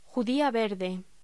Locución: Judía verde